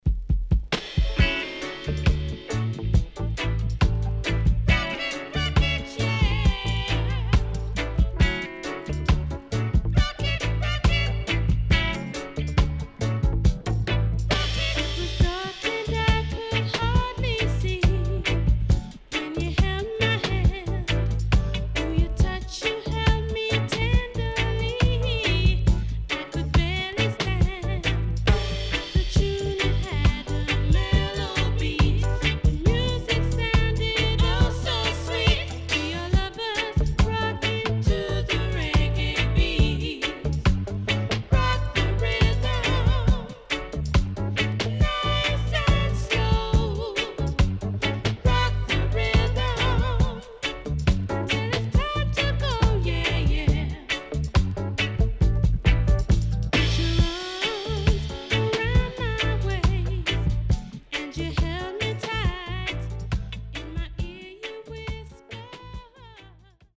【12inch】
SIDE A:少しチリノイズ入りますが良好です。